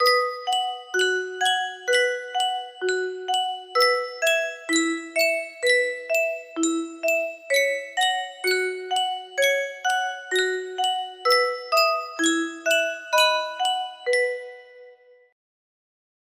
Sankyo Music Box - Ah So Pure TAE music box melody
Full range 60